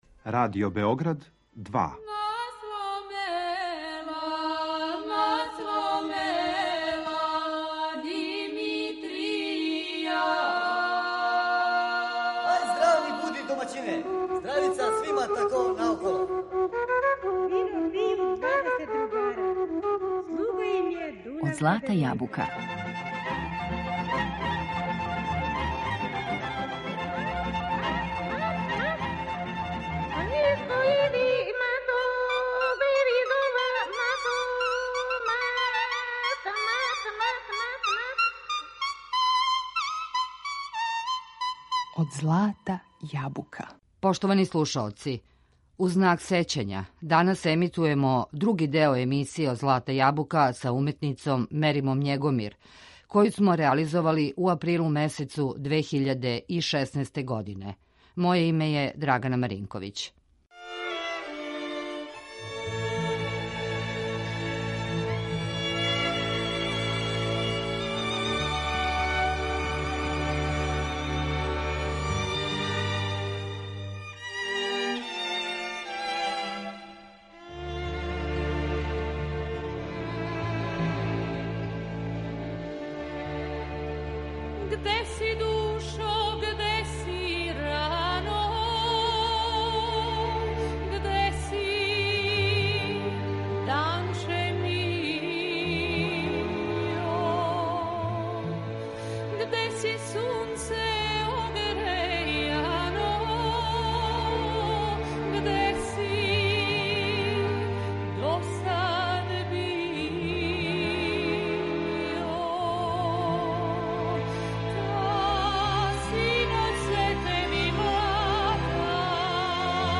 23. и 24. 11 - У знак сећања на уметницу Мериму Његомир, емитоваћемо две емисије Од злата јабука из априла 2016. године у којима нам је била гошћа.